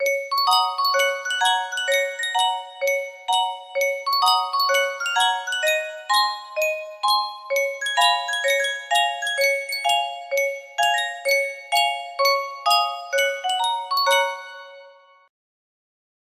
Yunsheng Music Box - IFAMDBIAFATCS 4750 music box melody
Full range 60